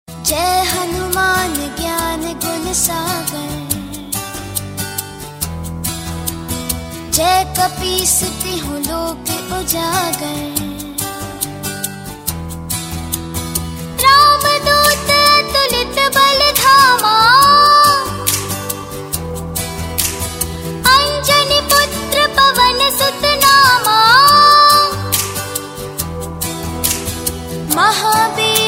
File Type : Bhajan mp3 ringtones